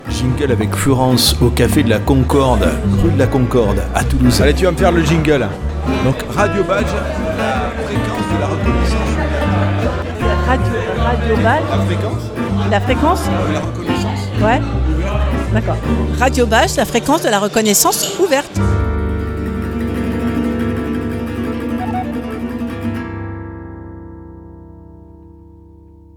exercice pas simple dans un environnement bien peuplé et bruyant ????